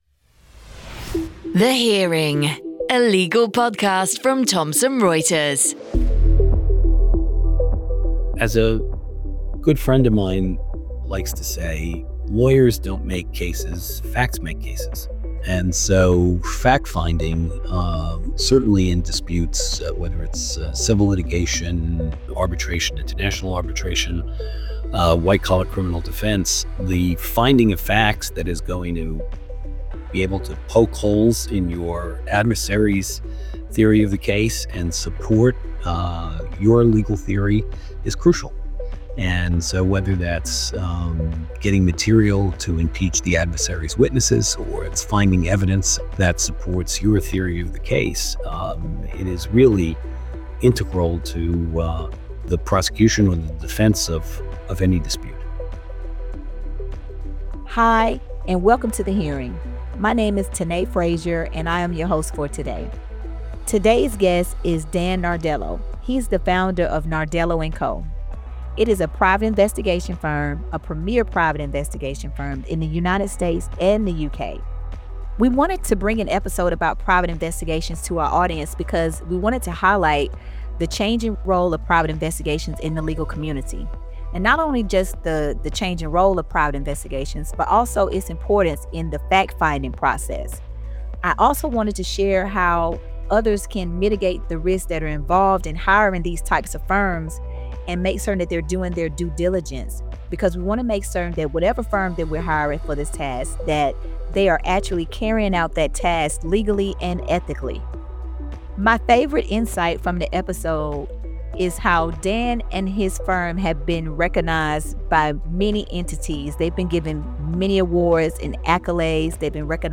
Listen to insightful discussions with lawyers, legal experts, and other interesting people connected to the legal industry. We delve into anything and everything – trailblazers in the law, the latest insights on legal innovation and trends, and how the profession is evolving. With hosts based in the UK and the US, we cover issues around the globe.